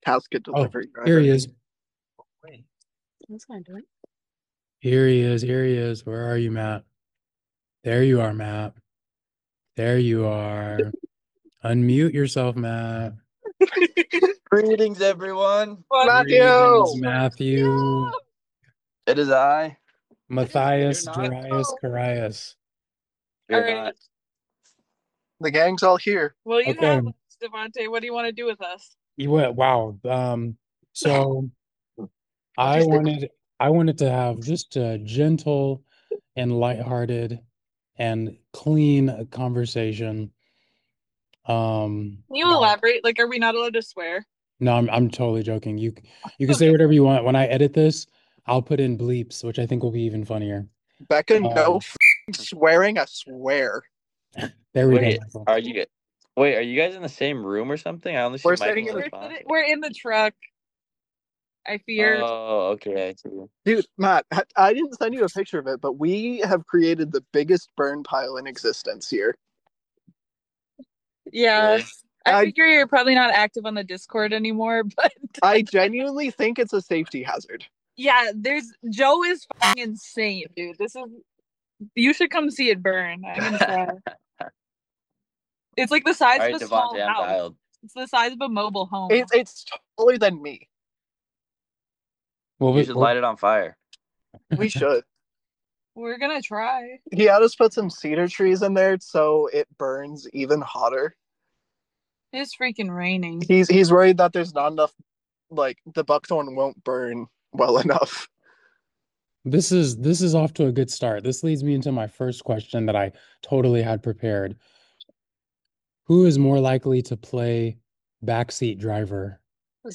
For my last post I decided to audio record me interviewing a fellow Central crew that has always made me laugh.